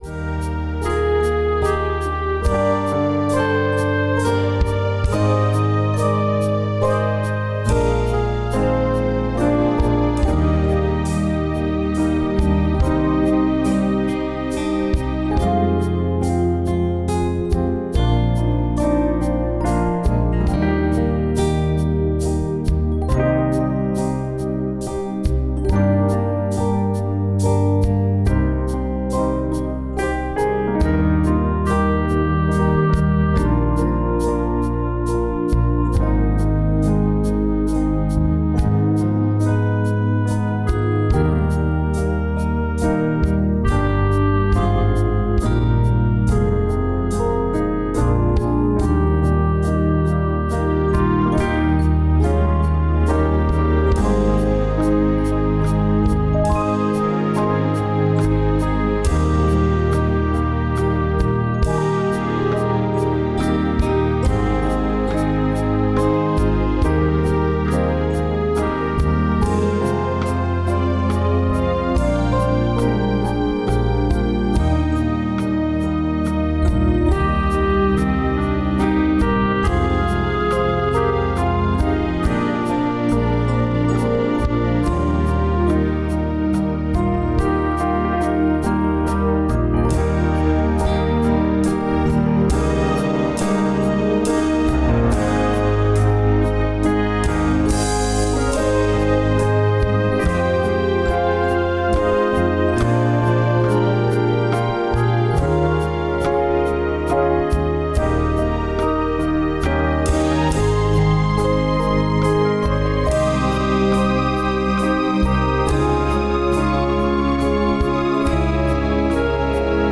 (SATB-Piano)
Listen to Accompaniment Track: